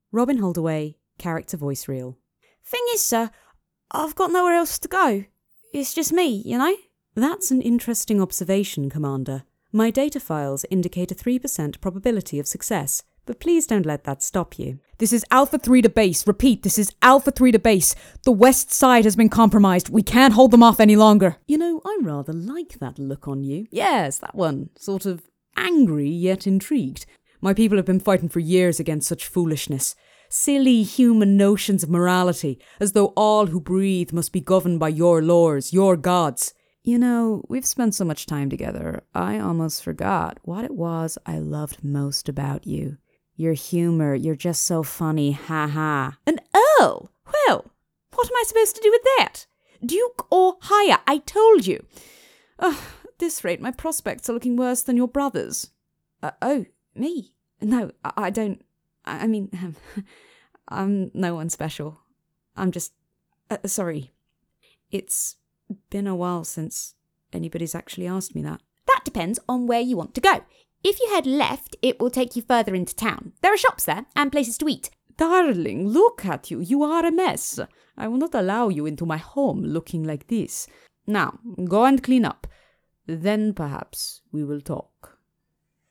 Native Accent: RP Characteristics: Familiar and Kind Age: 20-30 View on spotlight Commercial Character Audiobook Documentary Character - Young
Character-voice-reel-NEW.wav